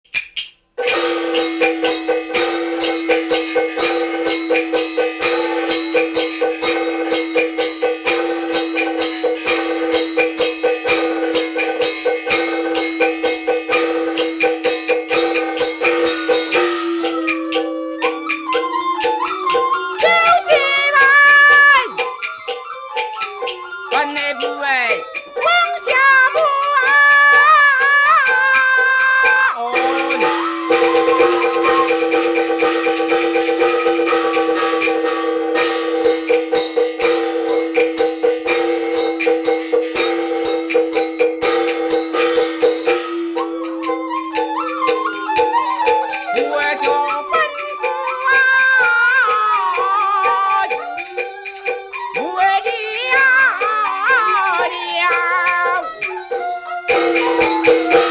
戲曲 - 新磨斧選段（刀子） | 新北市客家文化典藏資料庫